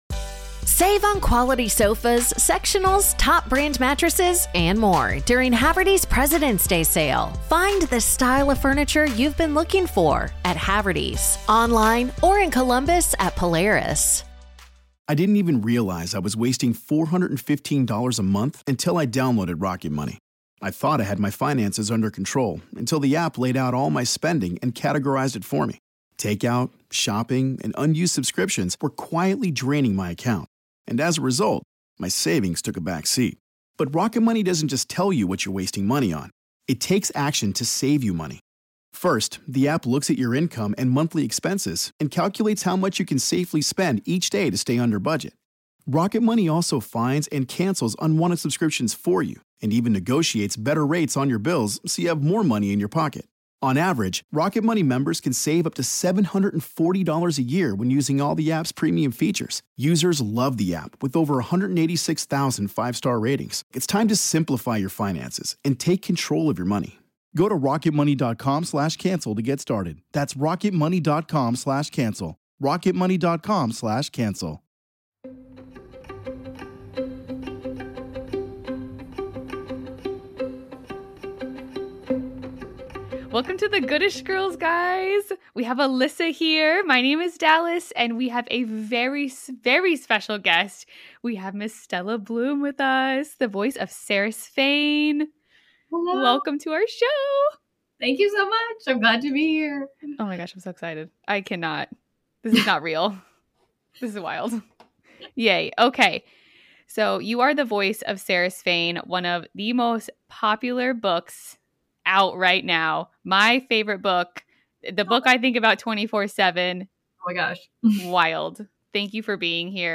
Make sure to listen to the very end to hear a line or two from Quicksilver in the Saeris Fane voice!